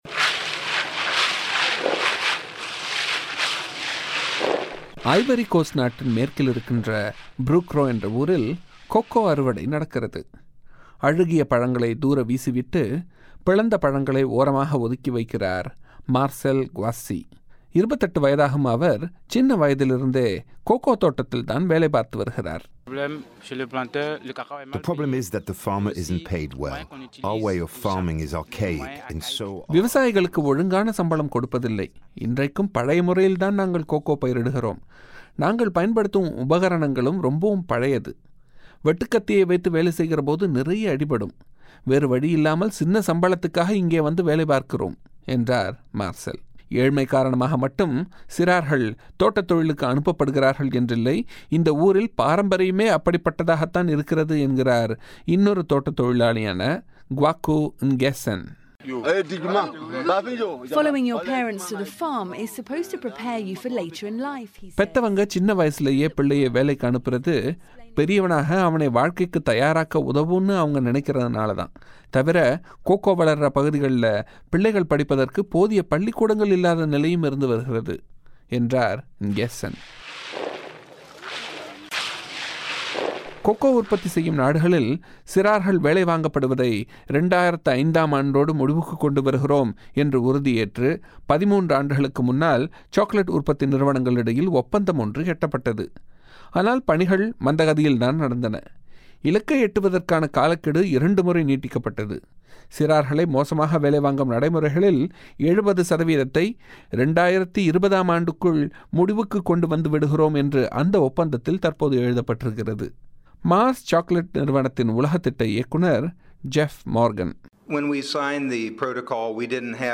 பெட்டகத்தின் தமிழ் வடிவத்தை நேயர்கள் கேட்கலாம்.